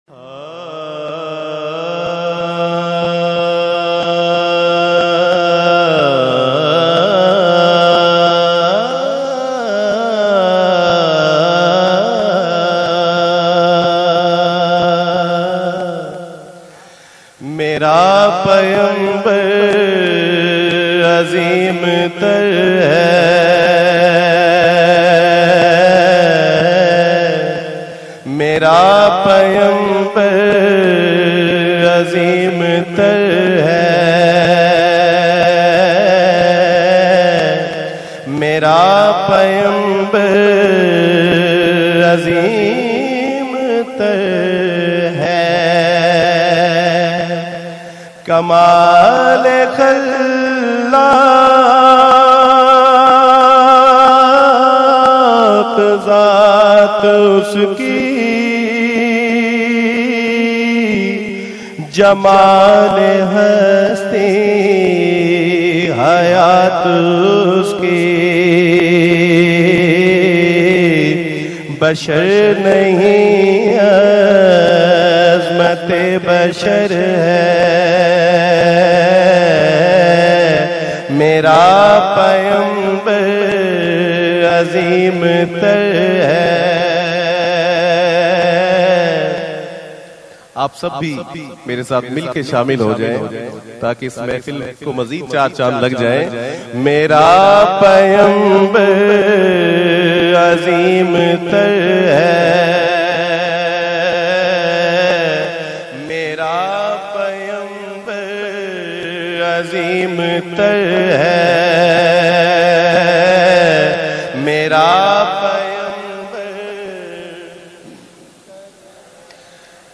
in best audio quality